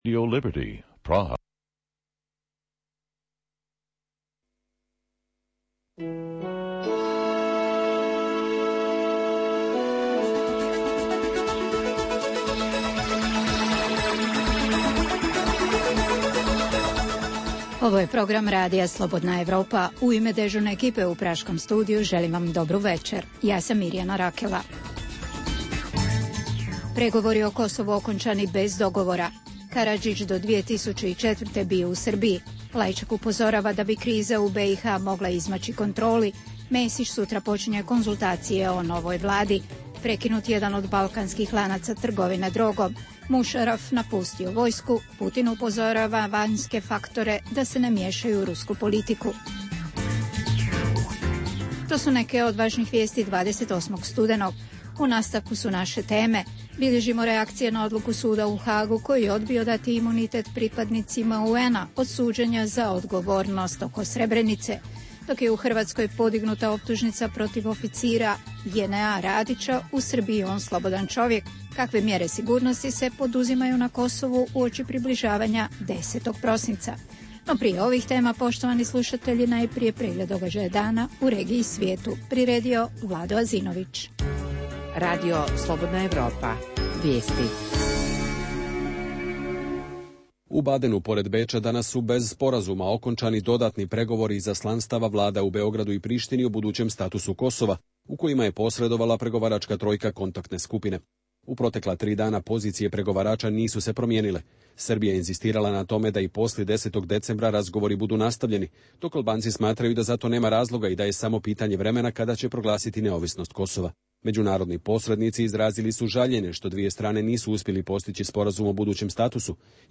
Da li Albanci iz Preševske doline imaju pravo isticati zastavu Republike Albanije? Intervju sa jednim od stranih eksperata o statusu Kosova i povezanosti kosovskog pitanja sa krizom u BiH.